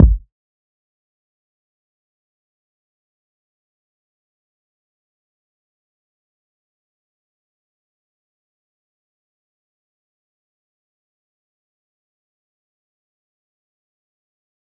DMV3_Kick 7.wav